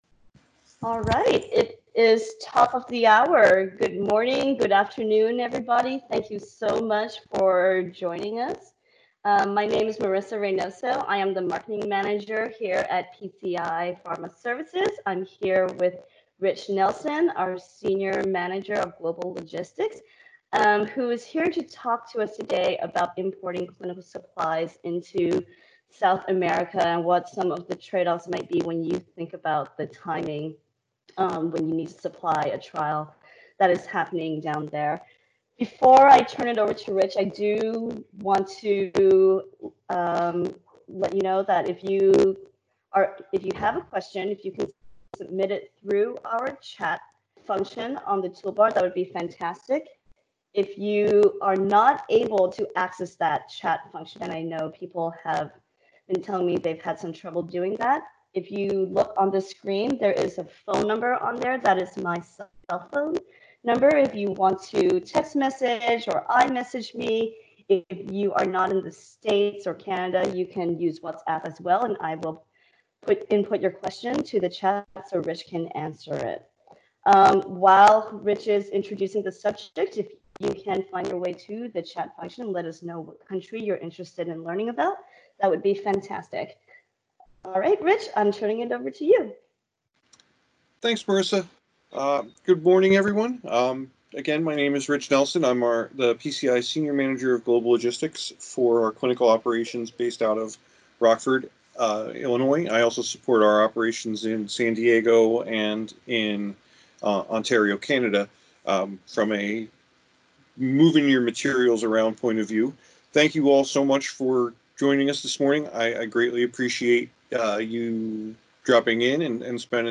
We host a weekly virtual Water Cooler Chat series, where our subject matter experts host informal discussions around a number of clinical supply hot topics. This week we are discussing importing clinical supplies into South America